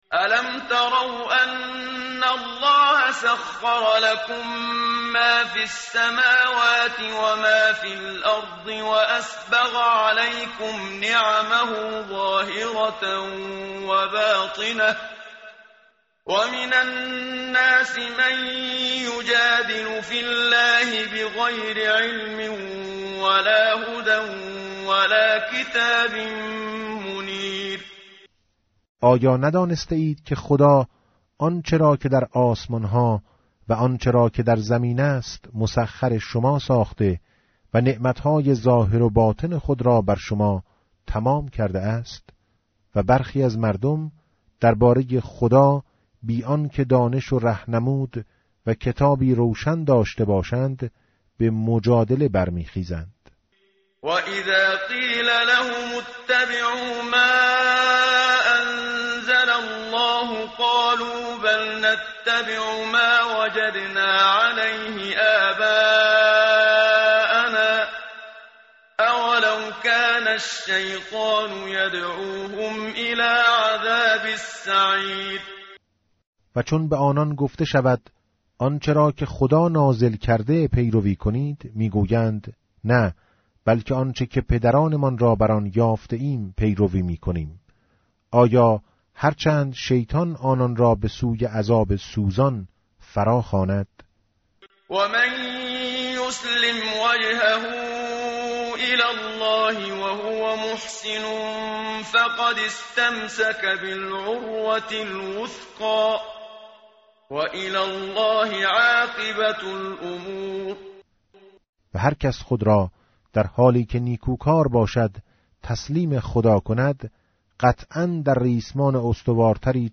متن قرآن همراه باتلاوت قرآن و ترجمه
tartil_menshavi va tarjome_Page_413.mp3